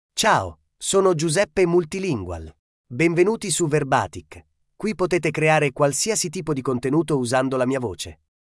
MaleItalian (Italy)
Giuseppe MultilingualMale Italian AI voice
Voice sample
Listen to Giuseppe Multilingual's male Italian voice.
Male